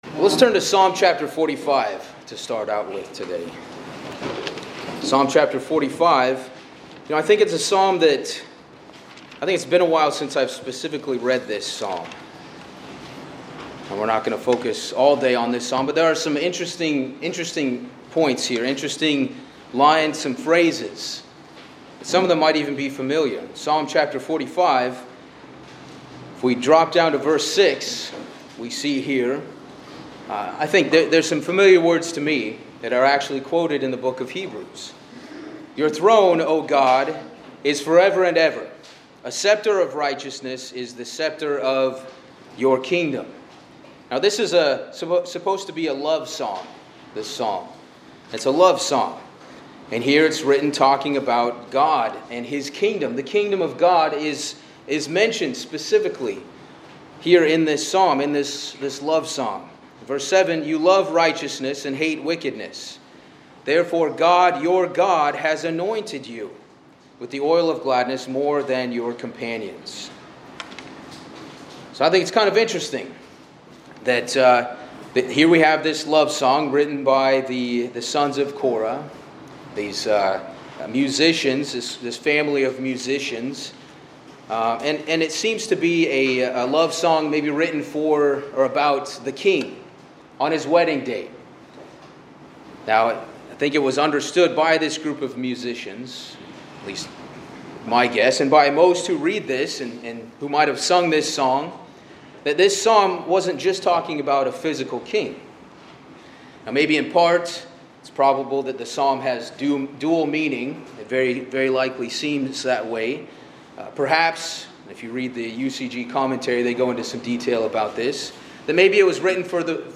This sermon explores the biblical theme of the "Bride of Christ," focusing on spiritual preparation for the return of Jesus Christ as the bridegroom. It draws extensively from various scriptures to illustrate the relationship between Christ and the Church, emphasizing the necessity of holiness, repentance, and readiness for the divine marriage.